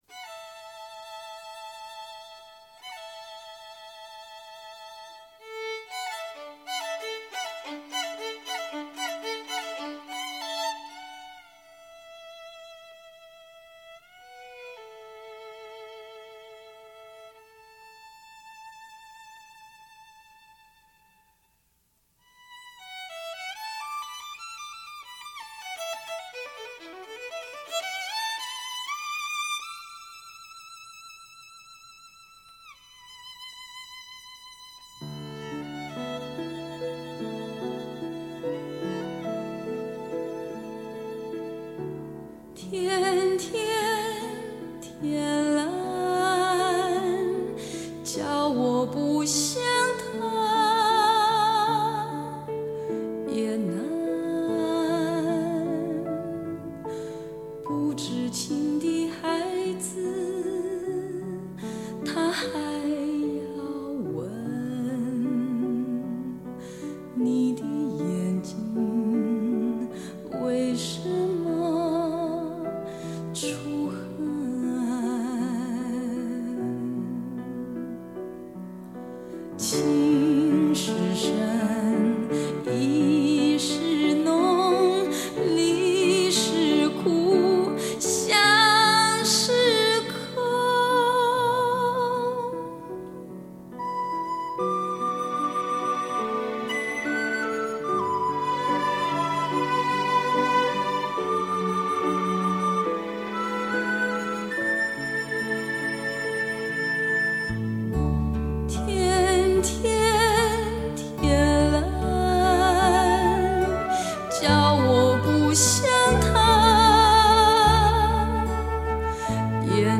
这张专辑让她的声音变得更清亮、更高亢。